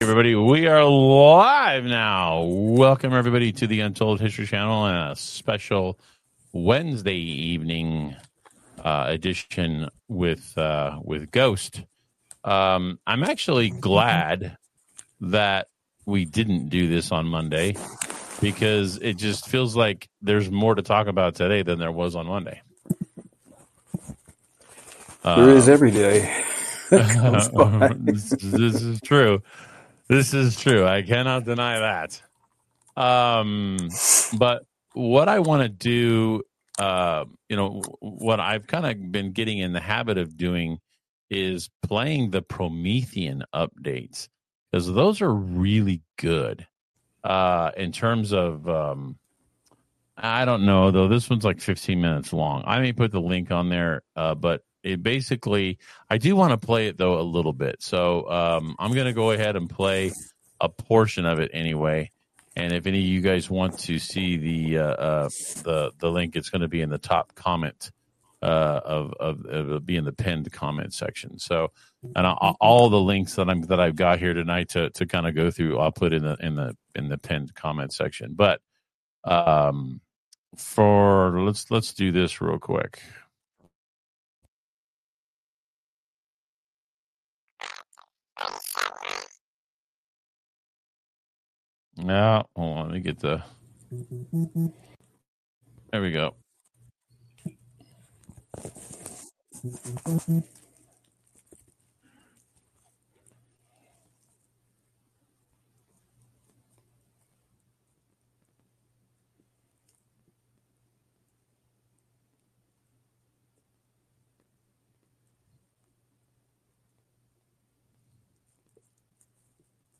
During a live session, The Untold History Channel faced audio issues while discussing President Trump's vision of achieving world peace through commerce instead of war. They explored how dogma can limit progress and the importance of embracing change. The conversation also touched on the influence of powerful individuals and the role of commerce in global politics.